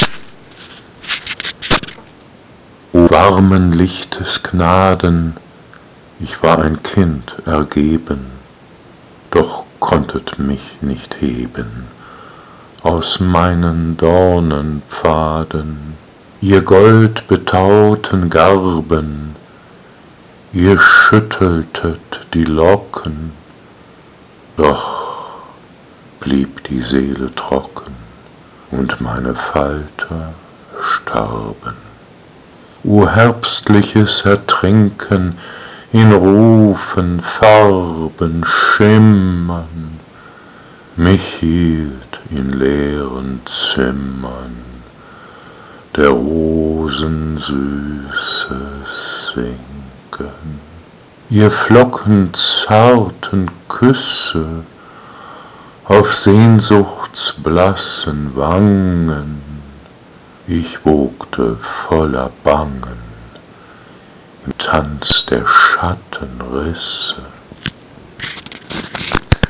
Rezitation: